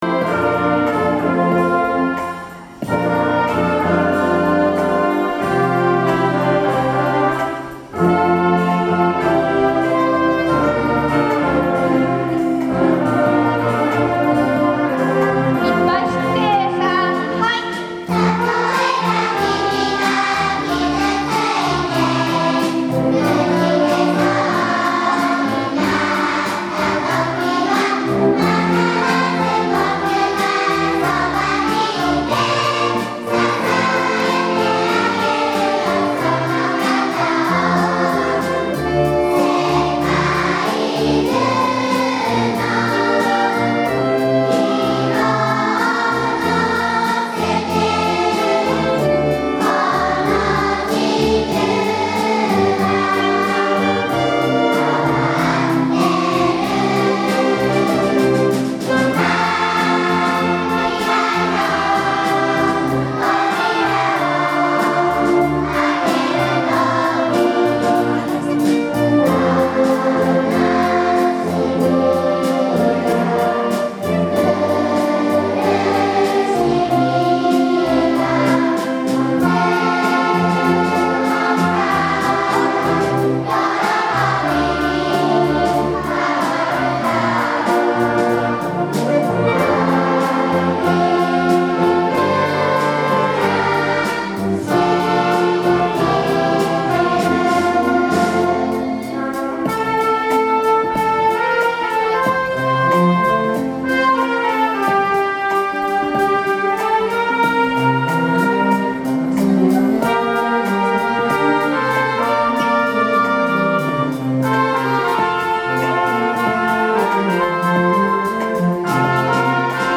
今日の朝の活動は、「音楽集会」です。
吹奏楽部６年生の伴奏に合わせて、全校生が手話付きで歌いました。